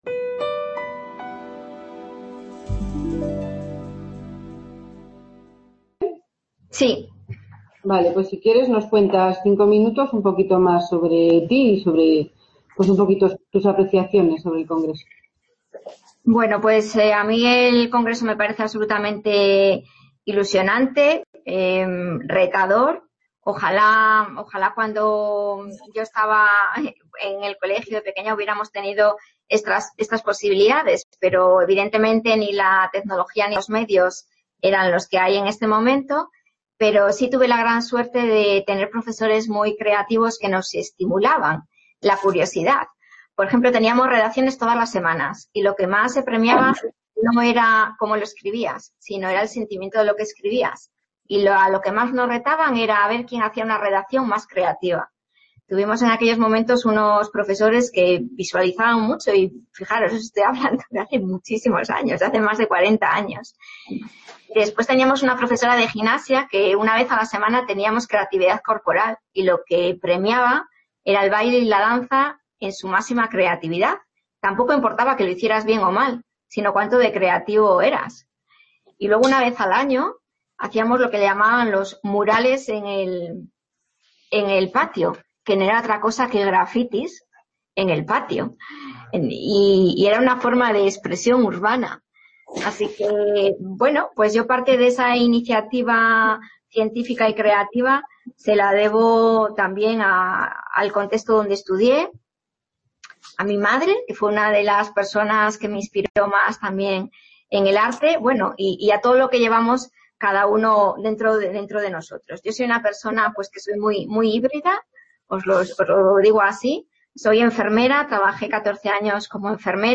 Description Congreso organizado por La Fábrica de Luz. Museo de la Energía junto con la ULE, la UNED y en colaboración con FECYT que se desarrolla en 3 salas CA Ponferrada - 2 Edición Congreso de Jóvenes Expertos. Cambio Climático.